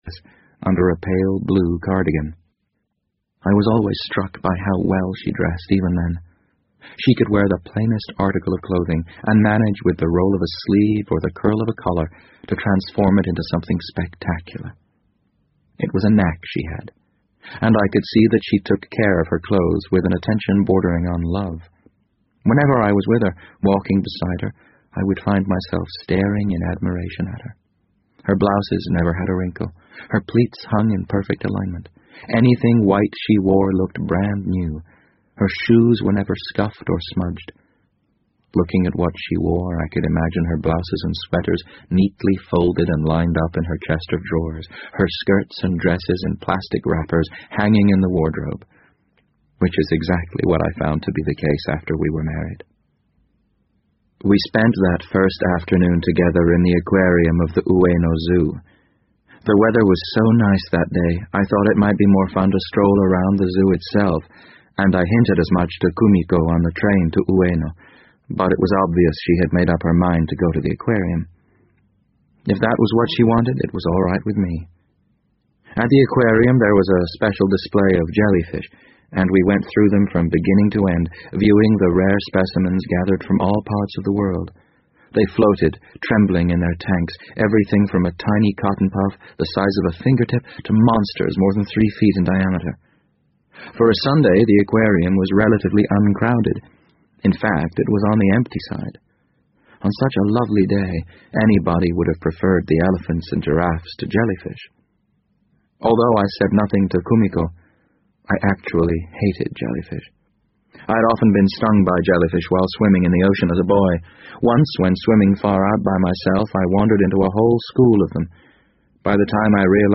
BBC英文广播剧在线听 The Wind Up Bird 006 - 11 听力文件下载—在线英语听力室